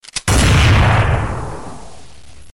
Barrett M82A1.MP3